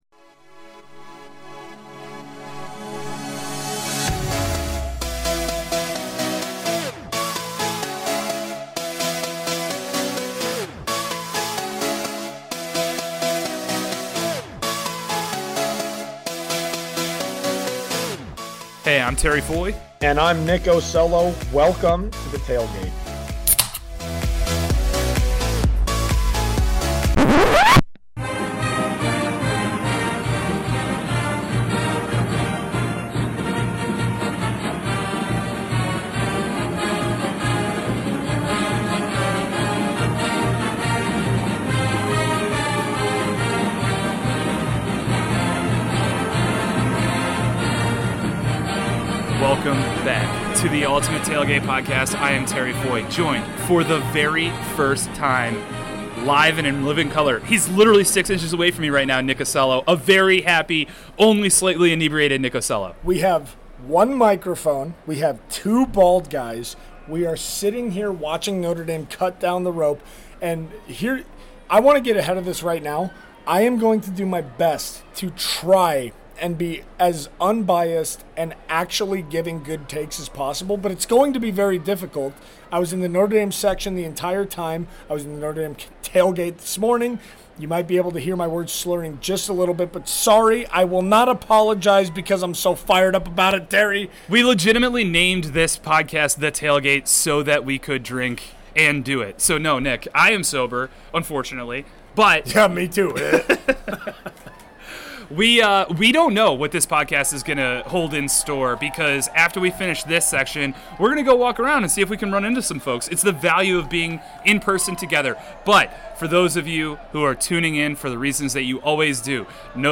Live from Lincoln Financial Field in Philadelphia